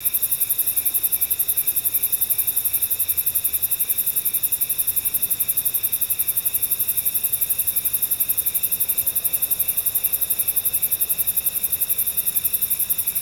Index of /90_sSampleCDs/E-MU Producer Series Vol. 3 – Hollywood Sound Effects/Ambient Sounds/Night Ambience
NIGHT AMB01L.wav